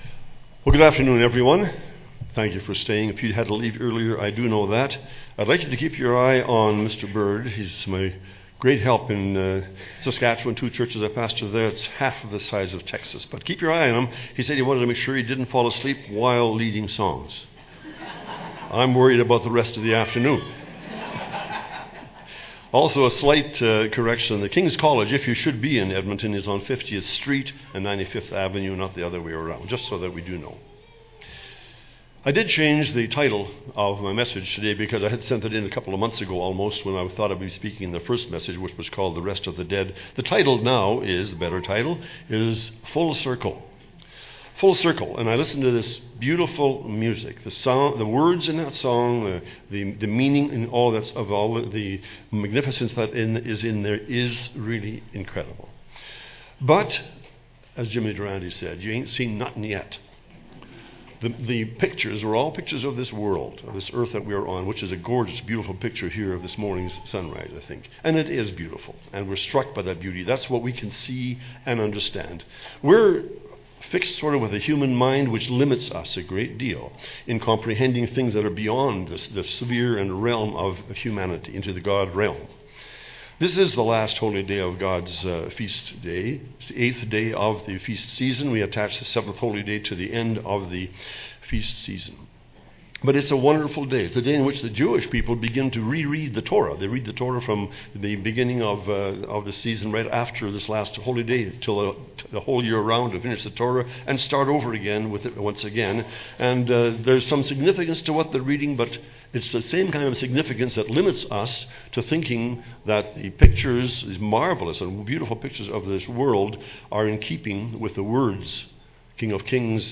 This sermon was given at the Canmore, Alberta 2014 Feast site.